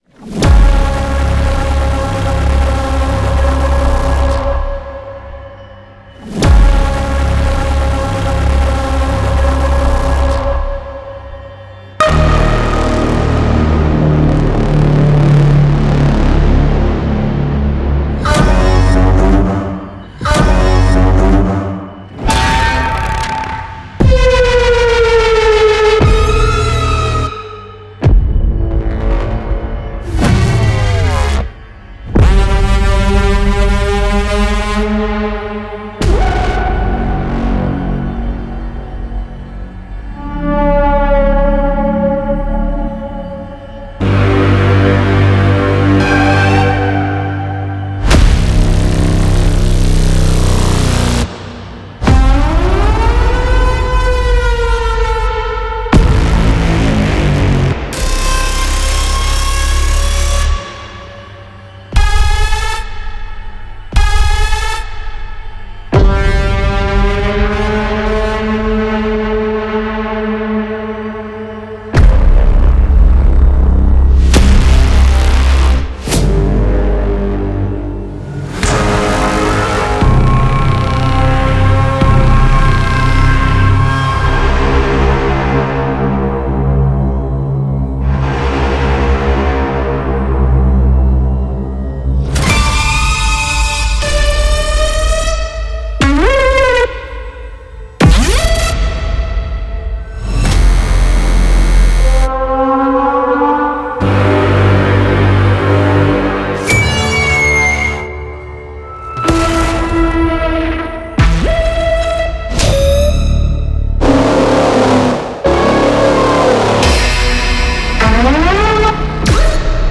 Genre:Filmscore
現代的なトレーラー制作のために精密設計されたSignal Forceは、アクション、スリラー、SFの強烈なインテンシティに対応する、55種類のアグレッシブなシネマティック・シグネチャーエフェクトを収録しています。
シンセティックなパワー、歪んだモジュレーション、メタリックな倍音、重厚なサブエネルギーを基盤に構築されたこれらのシグネチャーは、ひと振りでミックスを突き抜け、強烈な存在感を放ちます。
敵対的なアラートトーンからサイバネティックなベンド、機械的なプレッシャースウィープ、ディストピア的なサイレン、破壊力のあるローエンド・シグネチャーヒットまで、すべてのサウンドは編集を加速させ、カットの要所を際立たせ、トレーラーキューのインパクトポイントを支えるために制作されています。
Braams：深く力強いシネマティック・ヒット
Benders：トーナルなフォーススウィープとベンド
Alarms：敵対的なセキュリティトーンと警告信号
Siren Signatures：ディストピア的、緊急性のあるエネルギー
Synth Signatures：アグレッシブで未来的なアイデンティティ
55 Cinematic Signature Effects